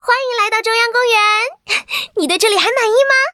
文件 文件历史 文件用途 全域文件用途 Fifi_amb_03.ogg （Ogg Vorbis声音文件，长度3.3秒，105 kbps，文件大小：43 KB） 源地址:游戏语音 文件历史 点击某个日期/时间查看对应时刻的文件。